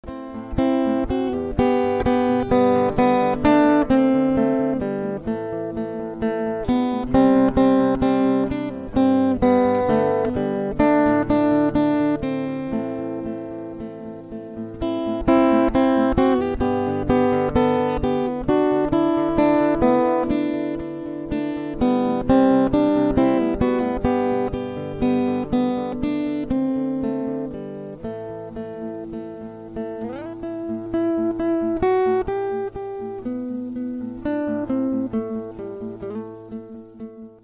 two instrumentals
for guitar
folk tune